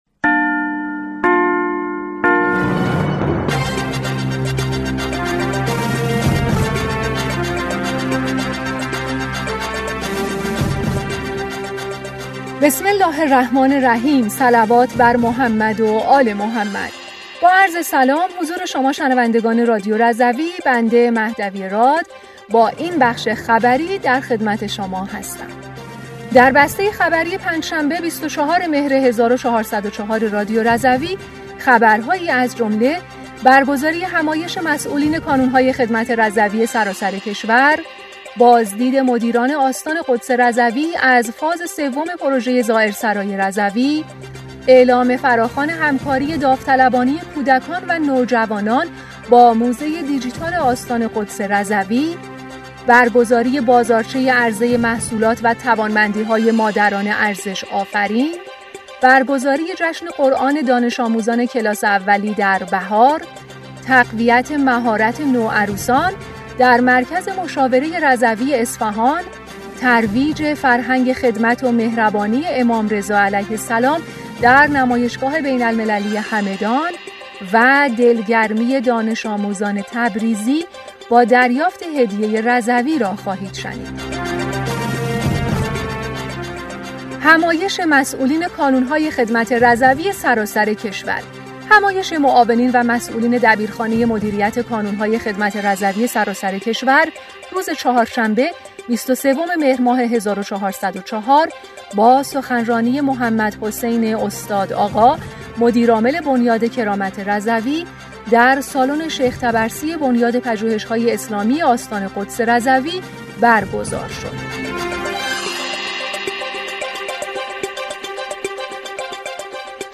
بسته خبری ۲۴ مهر ۱۴۰۴ رادیو رضوی؛